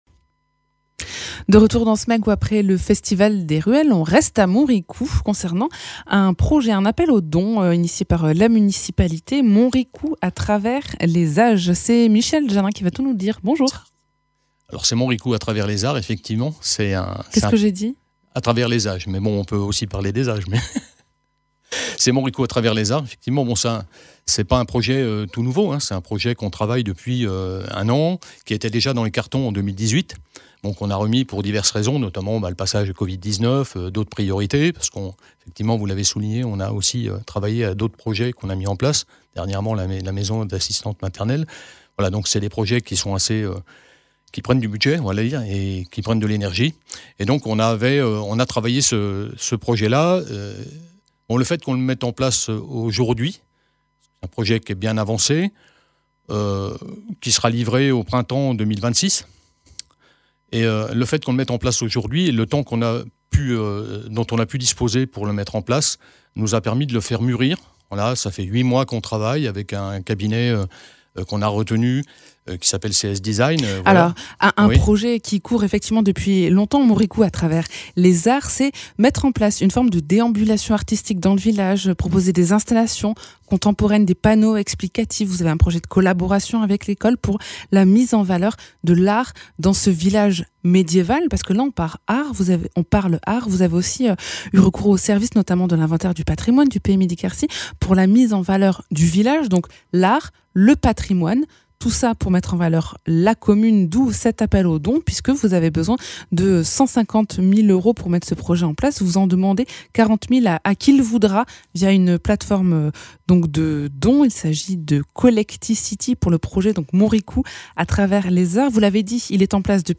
Interviews
Invité(s) : Michel Jannin, élu à Montricoux